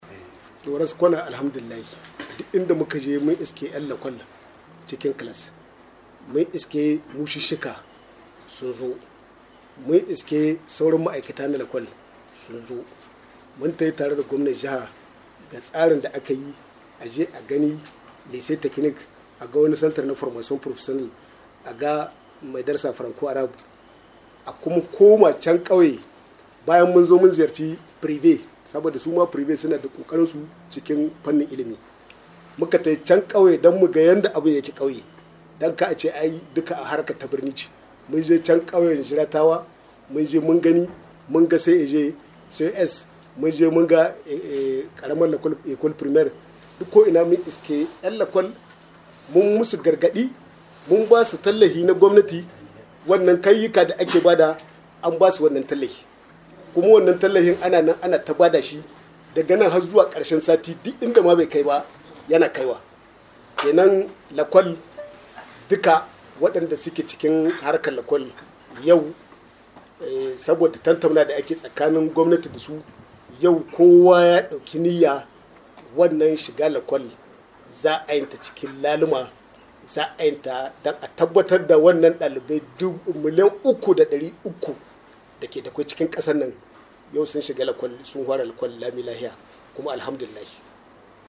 A la fin de cette tournée il fait le constat suivant au cours d’un point de presse.